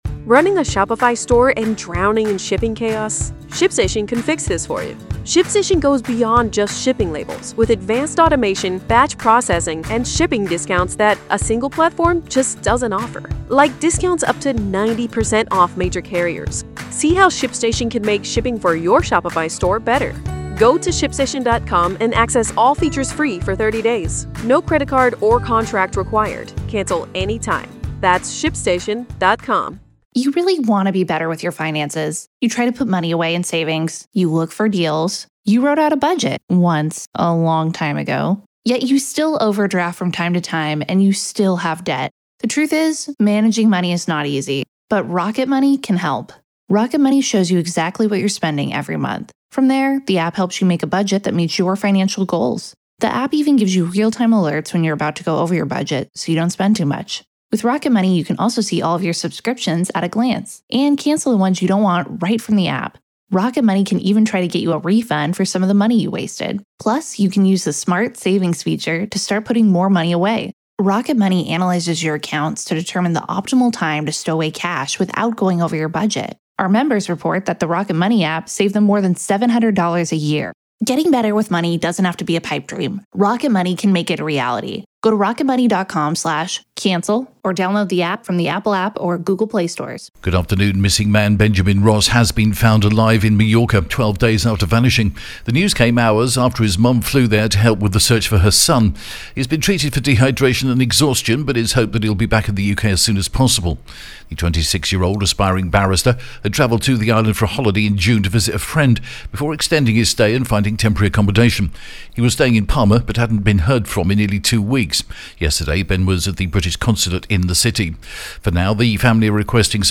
The latest Spanish news headlines in English: 23rd July 2024